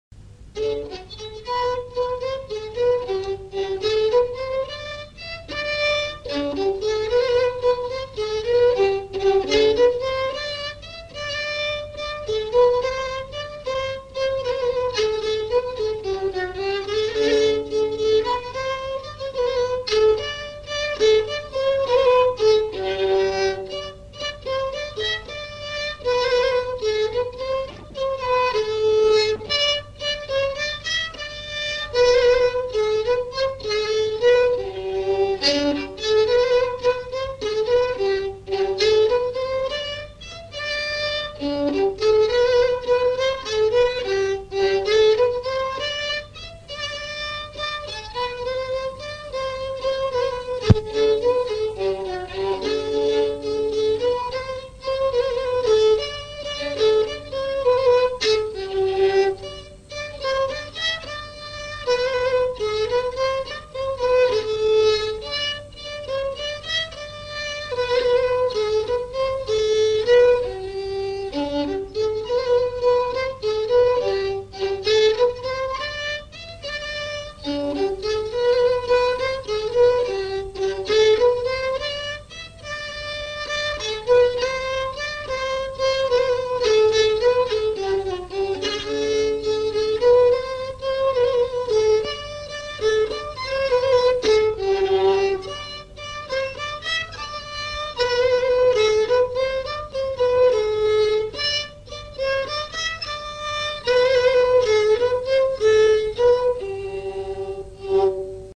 danse : valse
enregistrements du Répertoire du violoneux
Pièce musicale inédite